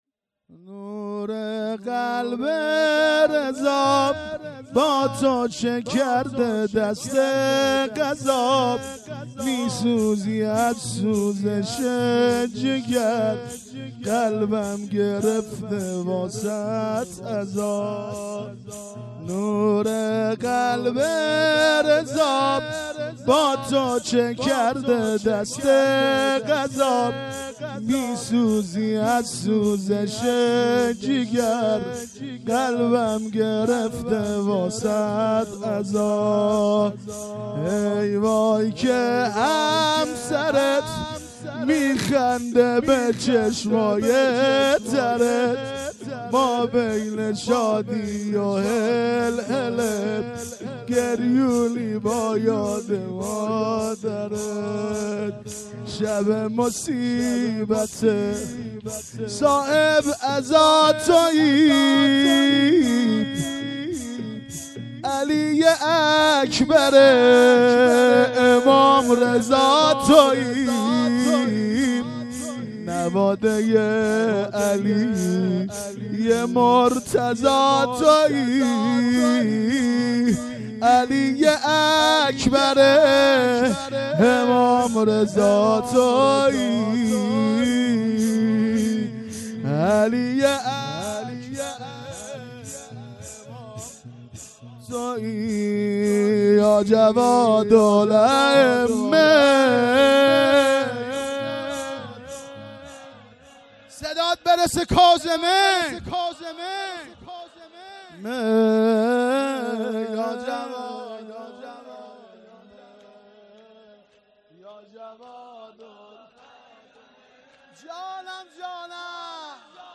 مراسم شهادت امام جواد علیه السلام ۱۴۰۴